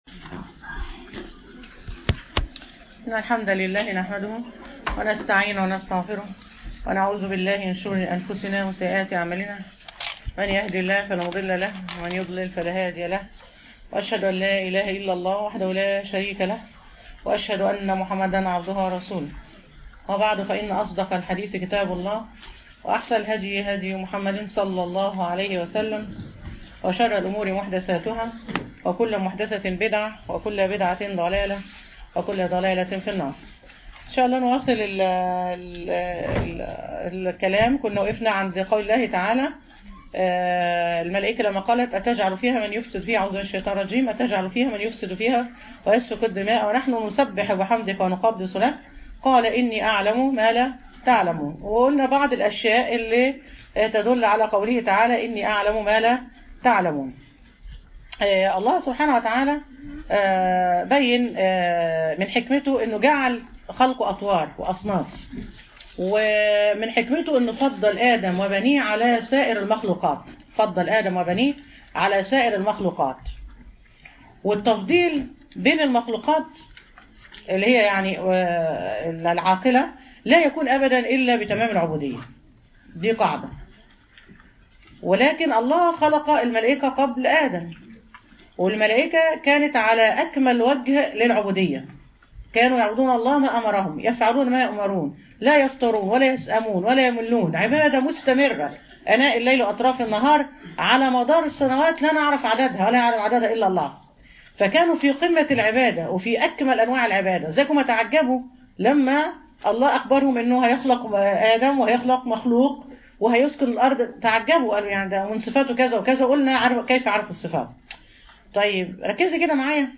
تدبر سورة البقرة المحاضرة السادسة من أية (30-33)